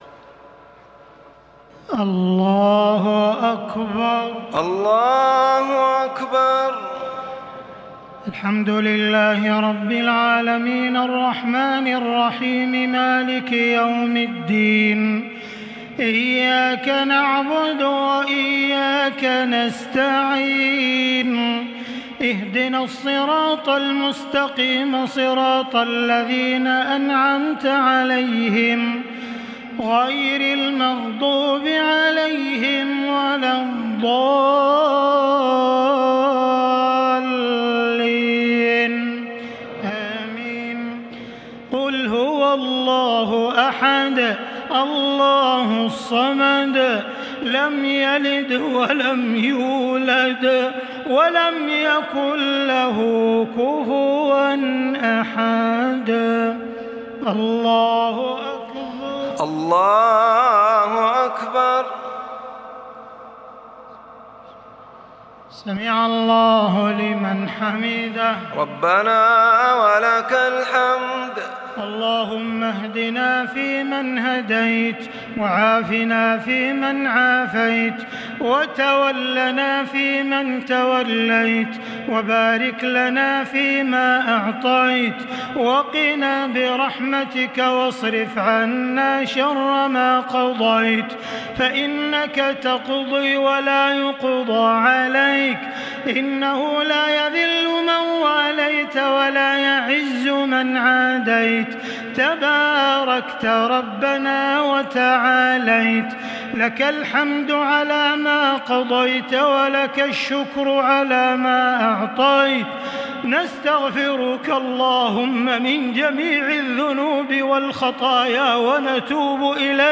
دعاء القنوت ليلة 27 رمضان 1440هـ | Dua for the night of 27 Ramadan 1440H > تراويح الحرم المكي عام 1440 🕋 > التراويح - تلاوات الحرمين